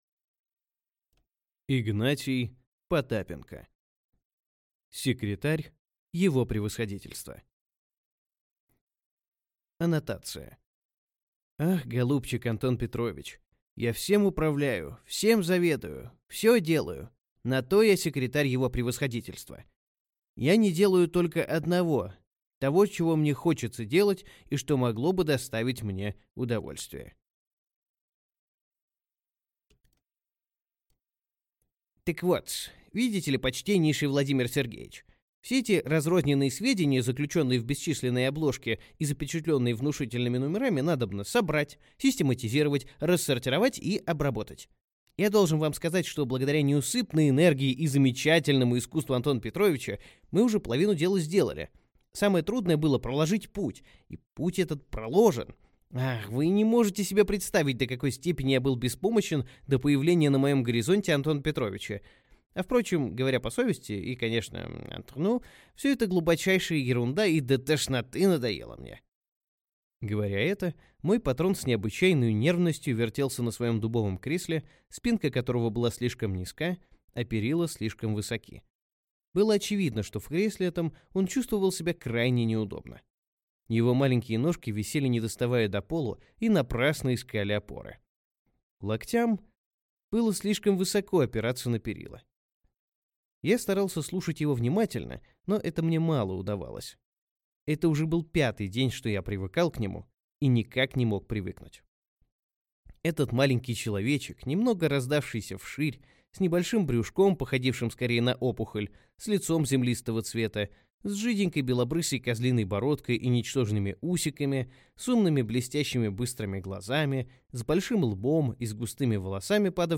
Аудиокнига Секретарь его превосходительства | Библиотека аудиокниг